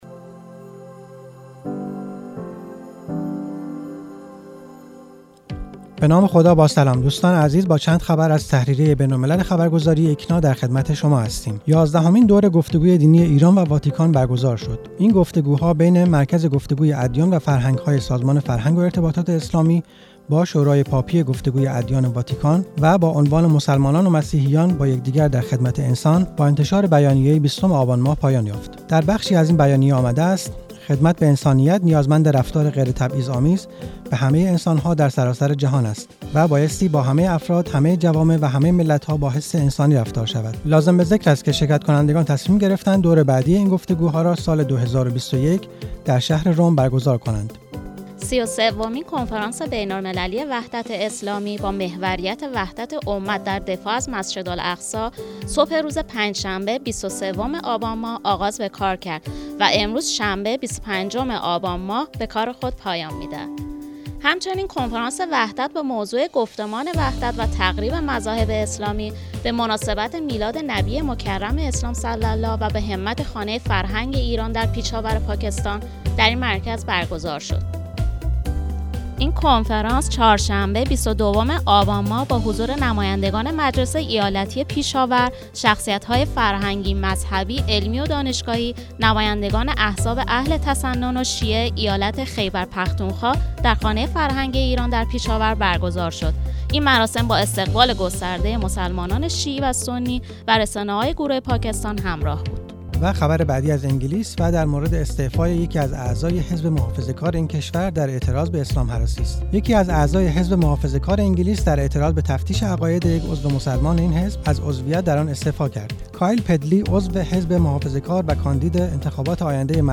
گروه چند‌رسانه‌ای ــ تحریریه بین‌الملل ایکنا در یک بسته خبری صوتی، اخبار جهان اسلام در هفته گذشته را مرور کرده است، با ما همراه باشید.